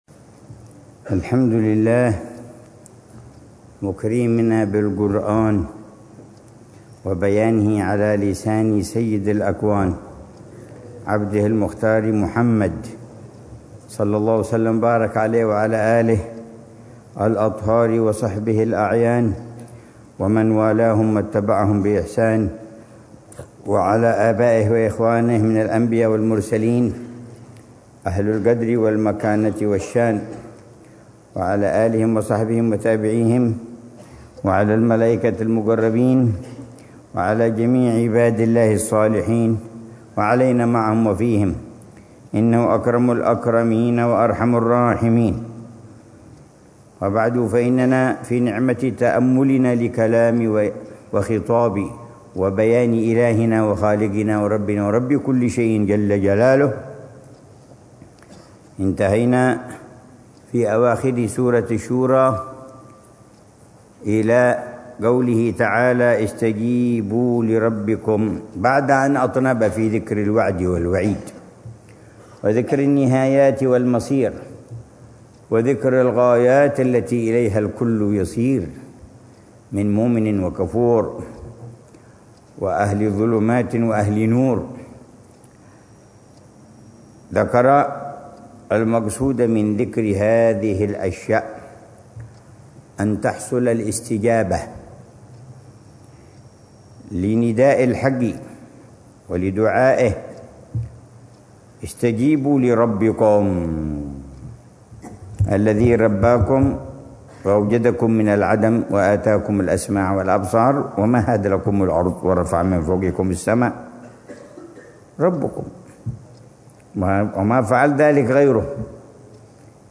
الدرس السادس عشر من تفسير العلامة عمر بن محمد بن حفيظ للآيات الكريمة من سورة الشورى، ضمن الدروس الصباحية لشهر رمضان المبارك من عام 144